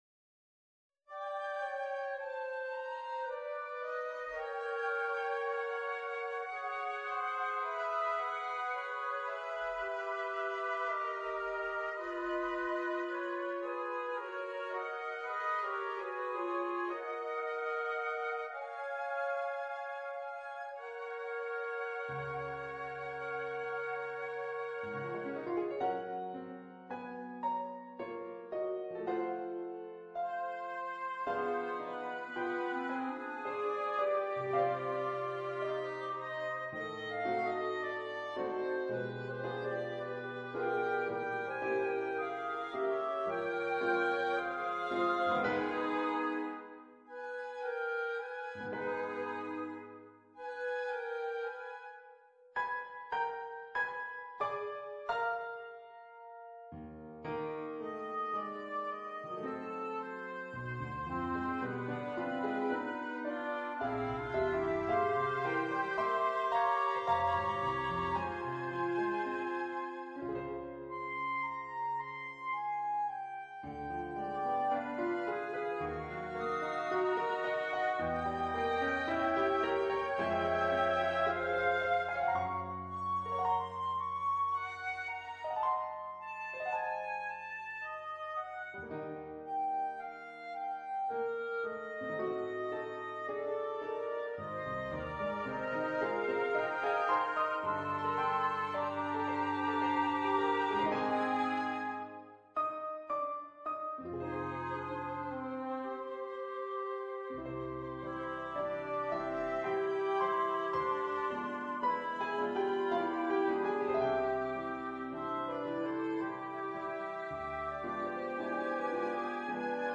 For Flute, Oboe, Clarinet and Piano